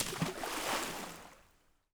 SPLASH_Small_05_mono.wav